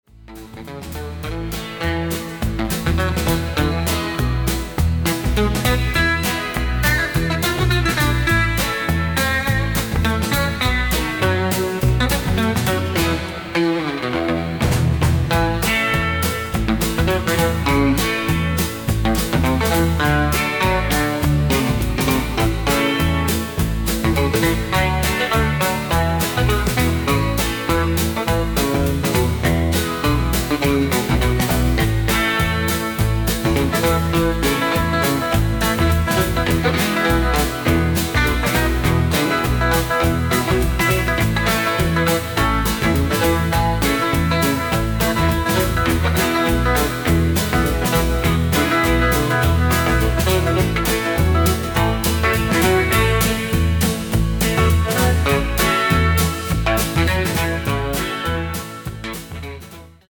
Mischung aus Country und Rock 'n Roll